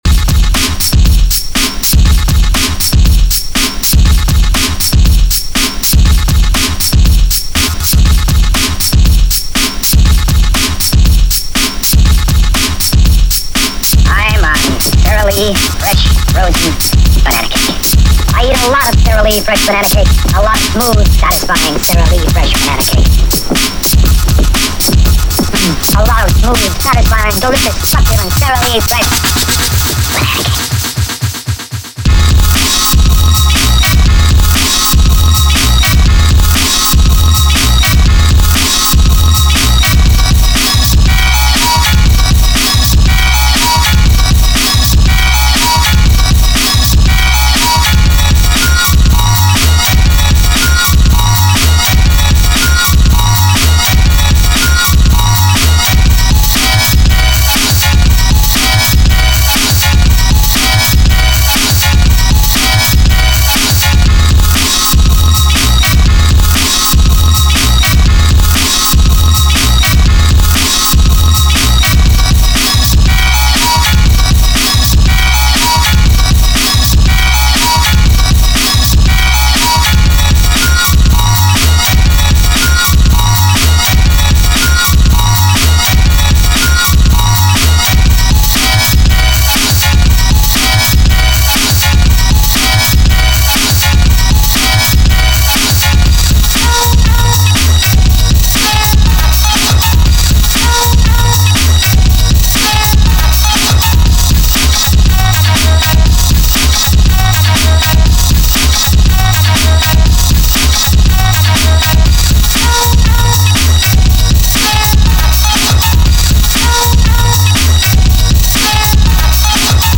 scenecore band of two.